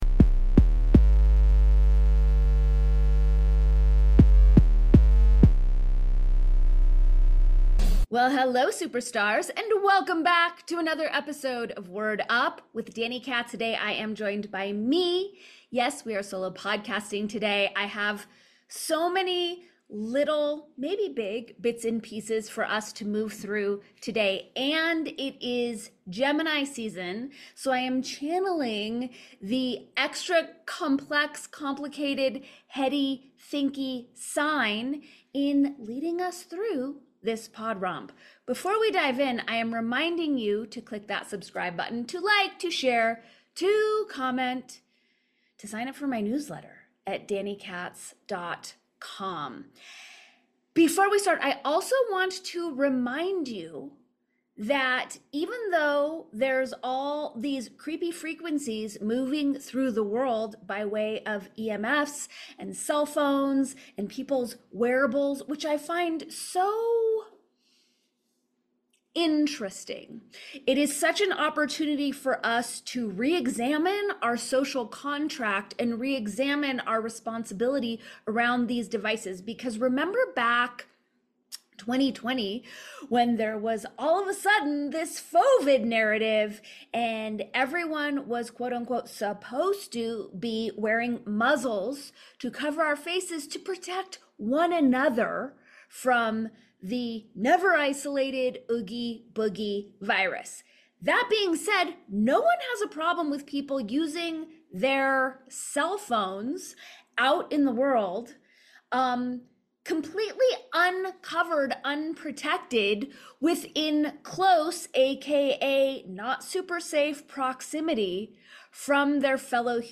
stopped by the podcast for a rollicking conversation about health